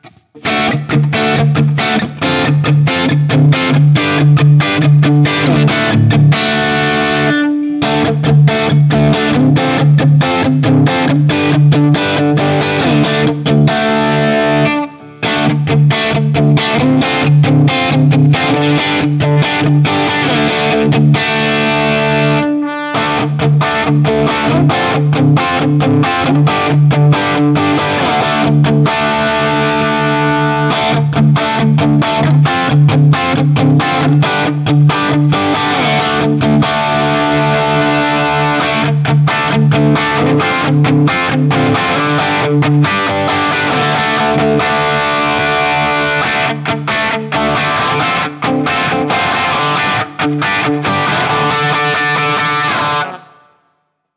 JCM-800
D-Shape Chords
For these recordings I used my normal Axe-FX II XL+ setup through the QSC K12 speaker recorded direct via USB into my Macbook Pro using Audacity.
Thus, each recording has seven examples of the same riff.